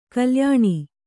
♪ kalyāṇi